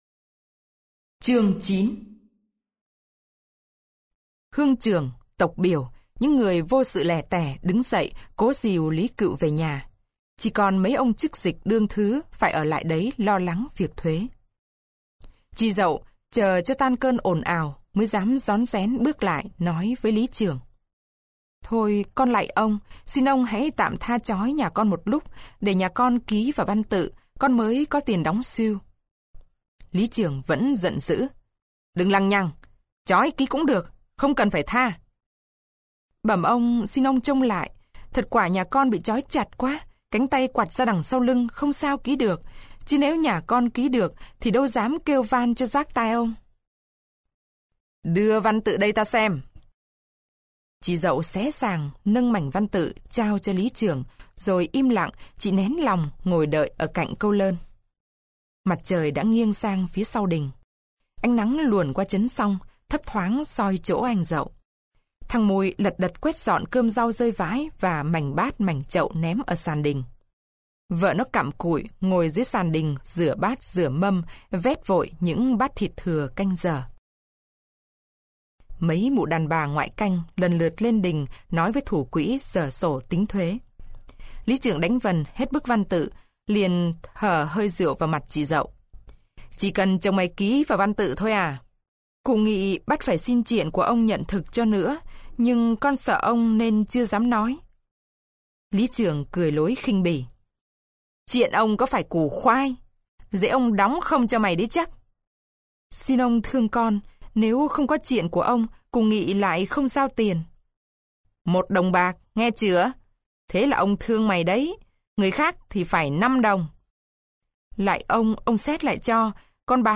Sách nói | Tắt đèn